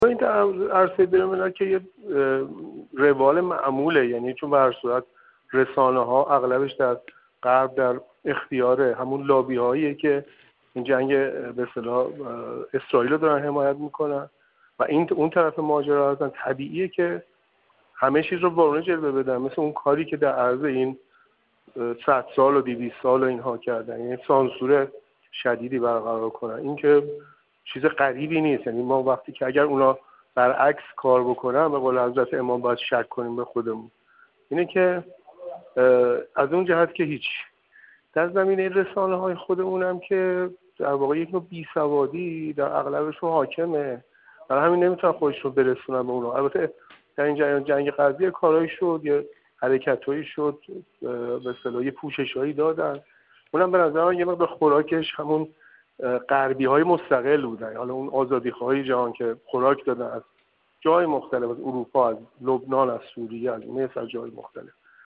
خبرگزاری بین‌المللی قرآن با این روزنامه‌نگار و سینماگر پیرامون کارکرد رسانه در پرداختن به جنگ غزه گفت‌و‌گویی انجام داده که در ادامه با آن همراه می‌شویم.